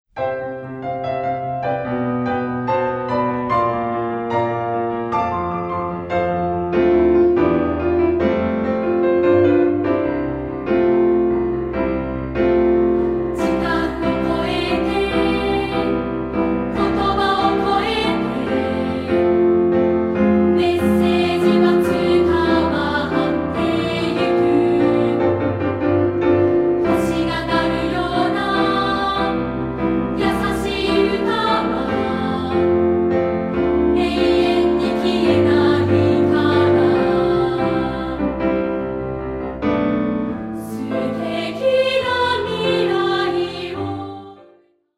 2部合唱／伴奏：ピアノ